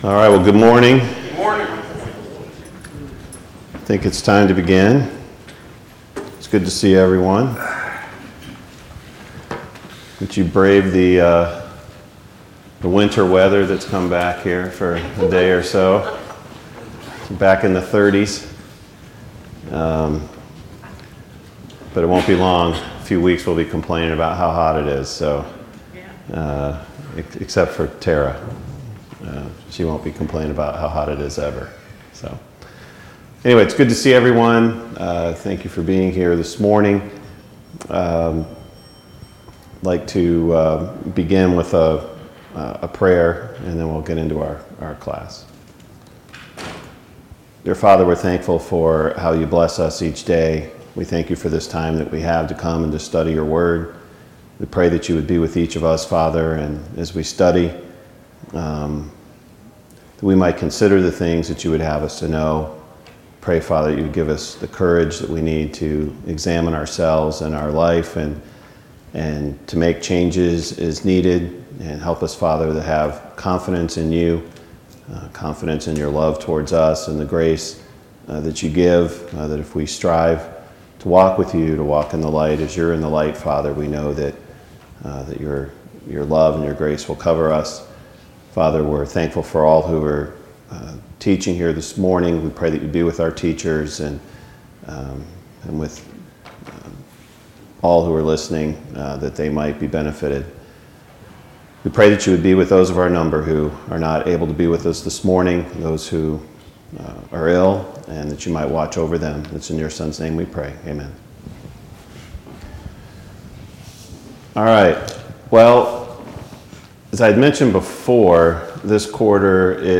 Series: A Study on the Apostle Peter Service Type: Sunday Morning Bible Class Topics